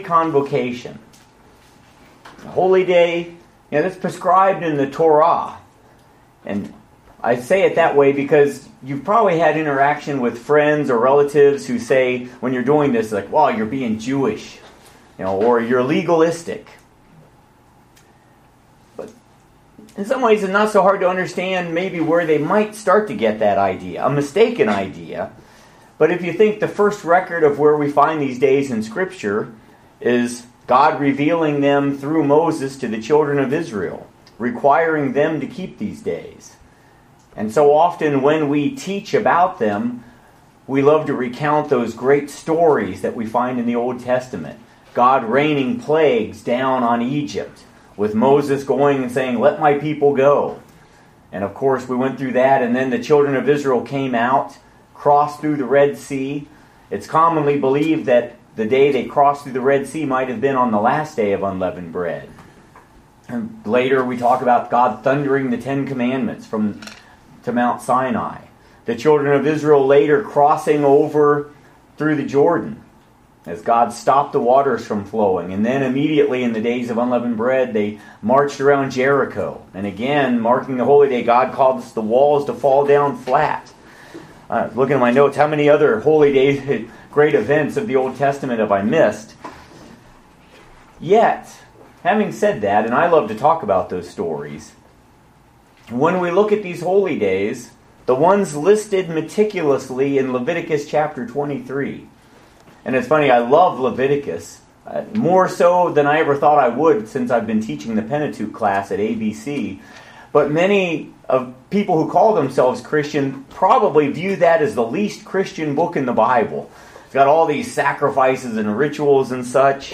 Join us for this excellent video sermon on the Spring Holy Days. There meaning and the reasons we keep them.